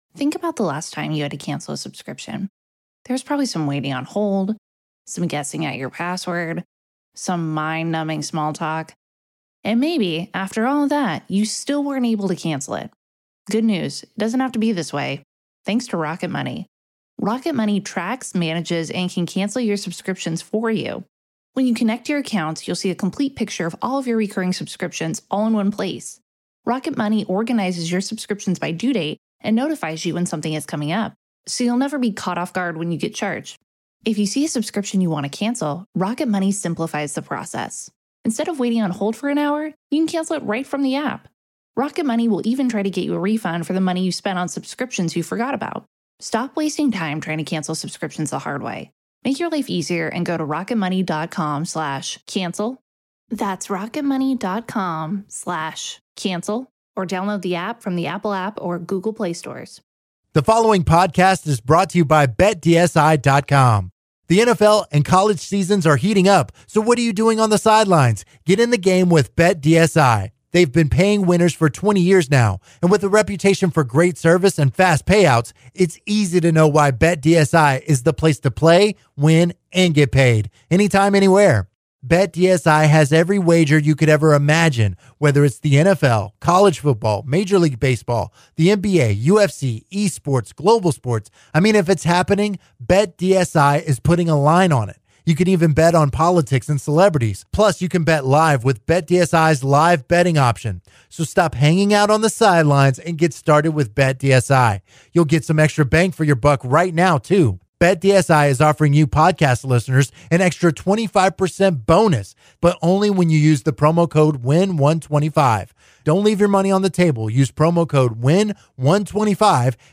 They take calls from listeners who give their take on Thanksgiving. They also debate what’s a good time to eat Thanksgiving dinner. They close the hour discussing the top 3 scoring teams in the NFL.